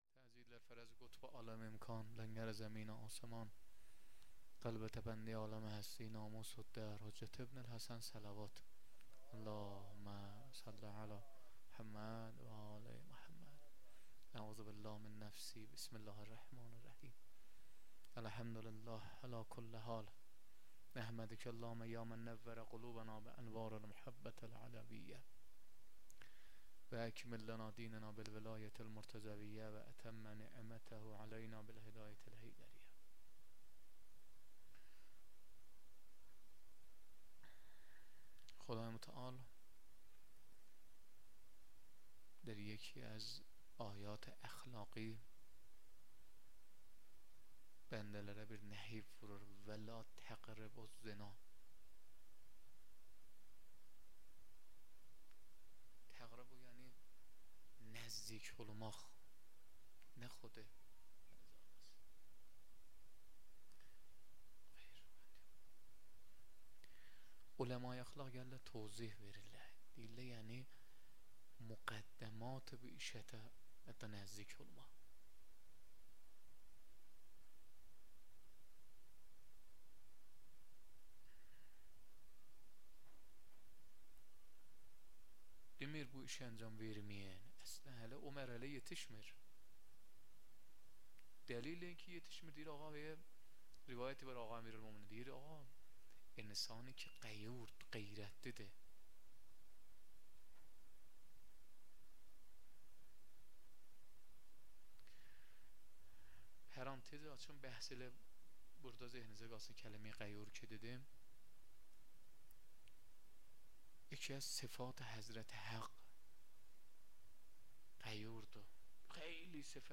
0 0 سخنرانی
مراسم هفتگی